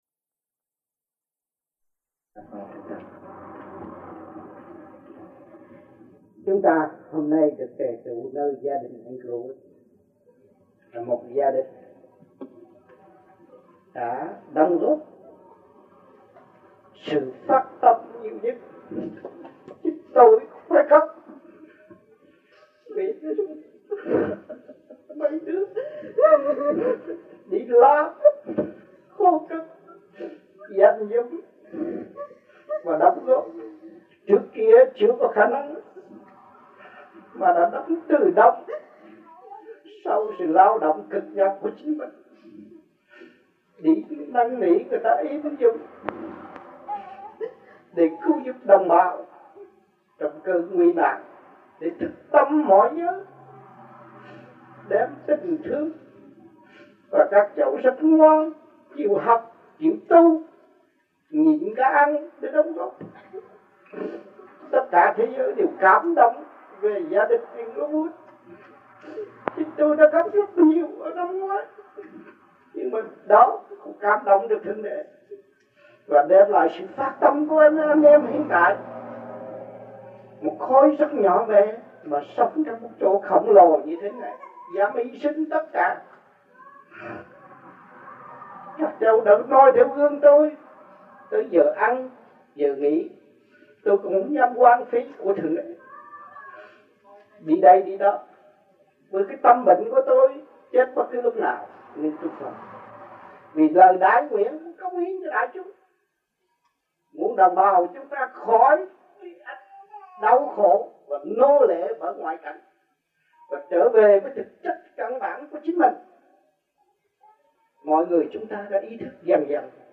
1982-07-04 - INGLEWOOD - HUẤN TỪ VÀ VẤN ĐÁP